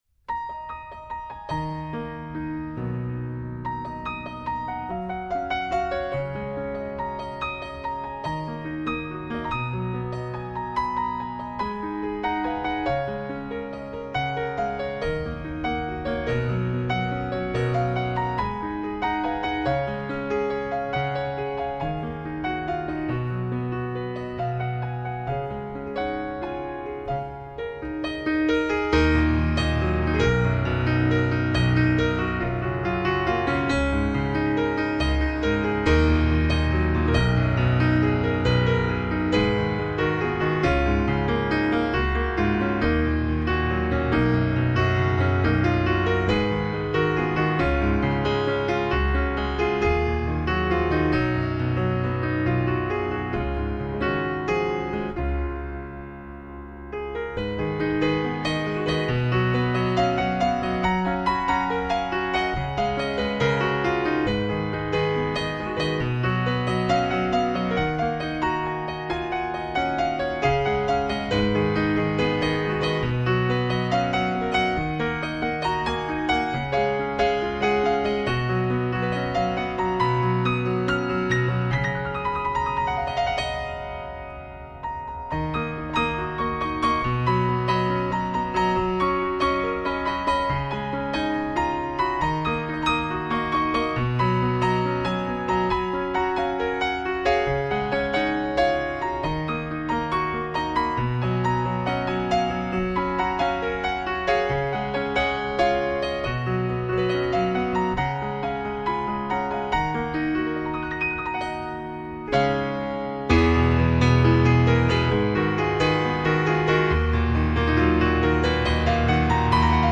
Genre...........: New Age，Solo Piano
Style...........: Contemporary Instrumental